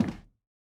added stepping sounds
Flats_Metal_Grate_002.wav